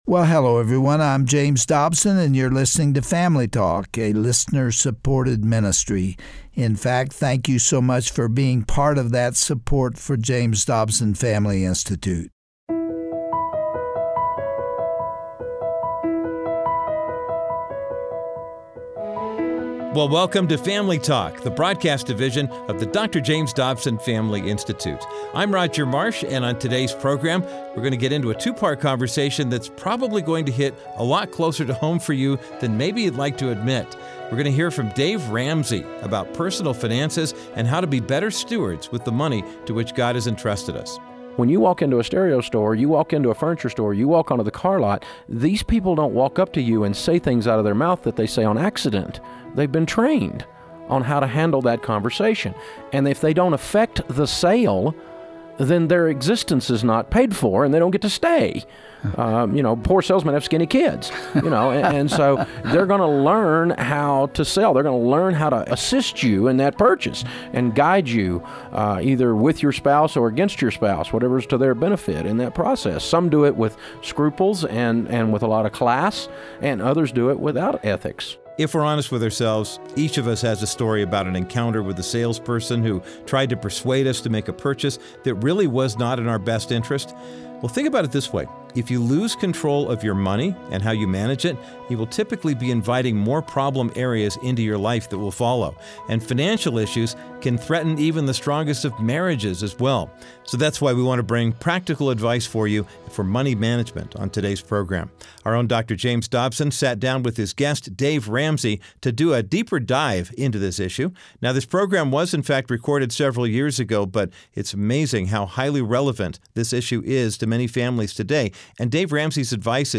On this classic edition of Family Talk, Dr. James Dobson talks with author and financial expert Dave Ramsey about the marketing schemes that threaten our fiscal stability. He shares his experience of becoming a multimillionaire by the age of 26, and how he crashed and burned by the time he was 30.